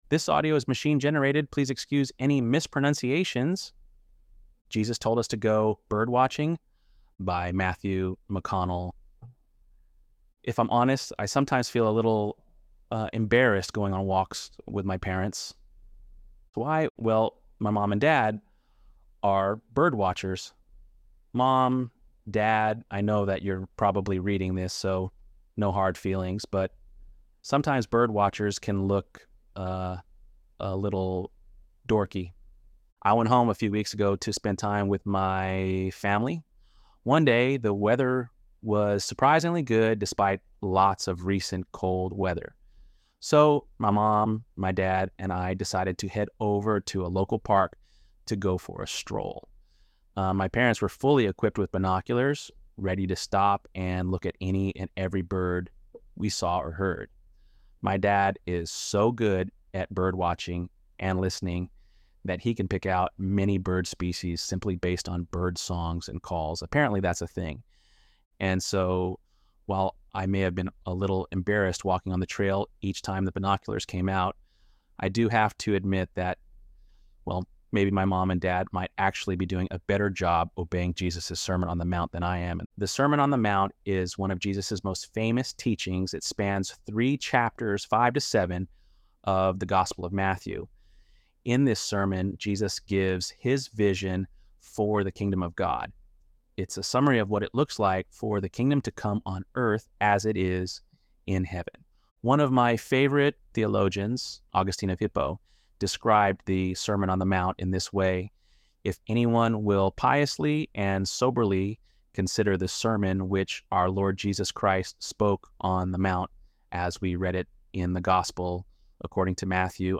ElevenLabs_7.23_Birds.mp3